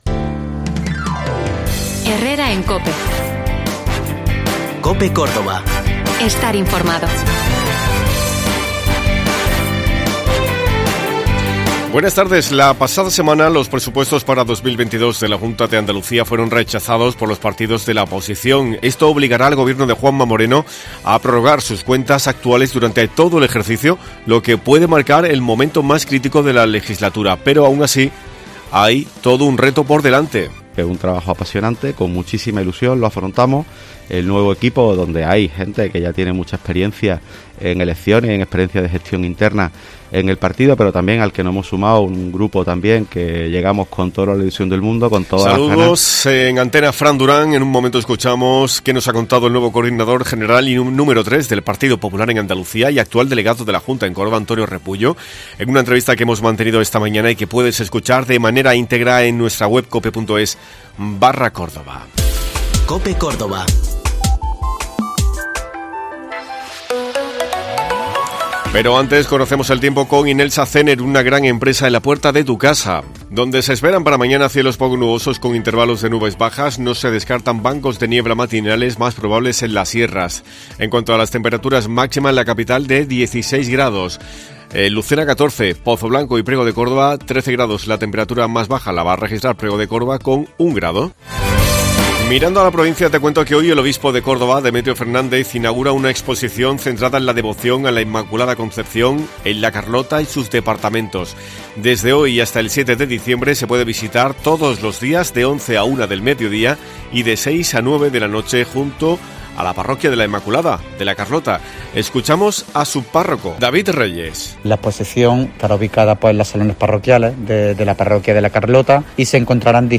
Hemos escuchado un estracto de su entrevista, la cual puedes escuchar de manera íntegra AQUÍ.